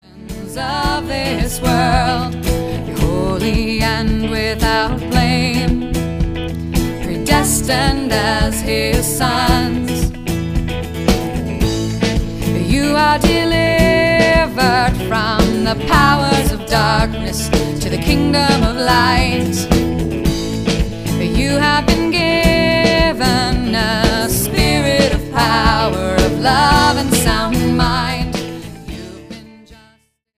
Music CD